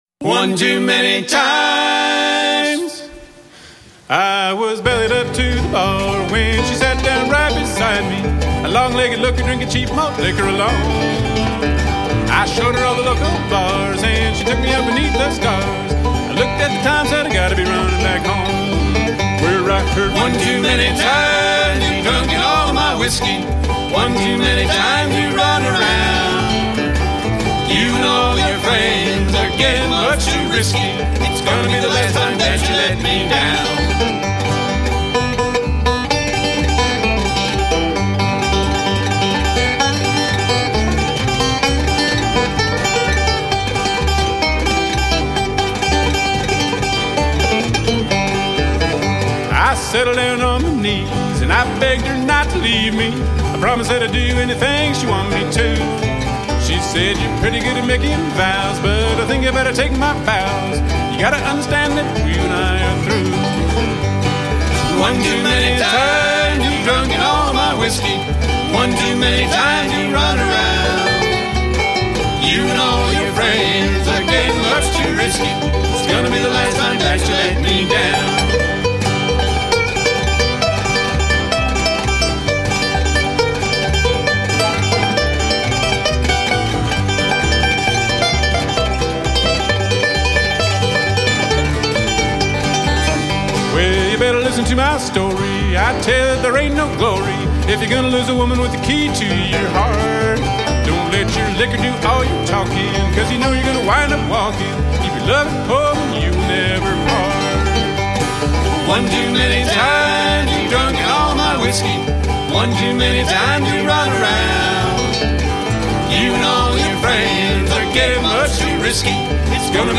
a collection of original vocal songs
Recorded live on the road during summers 2010 and 2011.